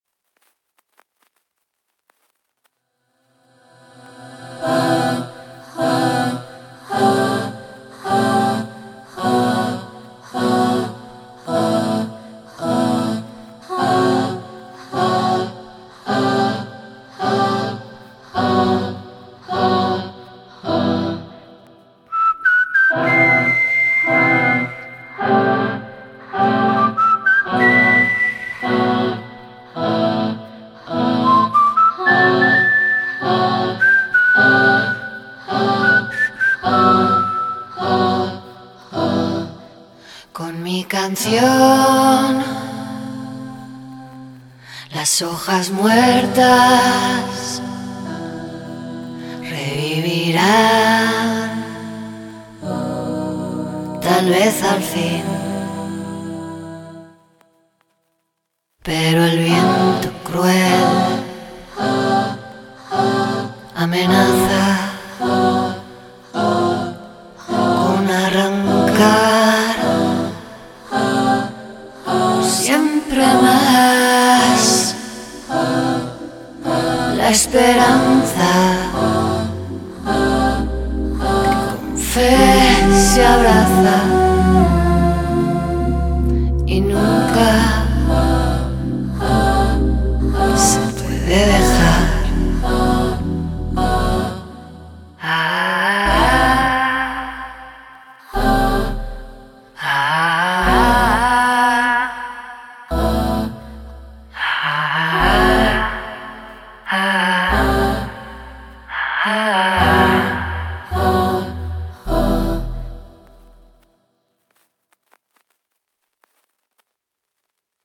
background music of the jazz standard